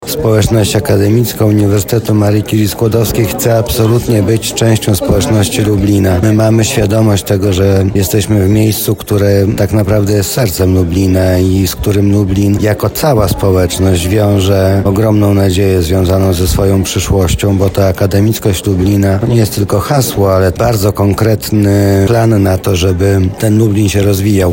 Zgodnie z tradycją społeczność Uniwersytetu Marii Curie-Skłodowskiej zebrała się w pierwszym tygodniu nowego roku.
Mariusz Banach– mówi Mariusz Banach, Zastępca Prezydenta ds. Oświaty i Wychowania.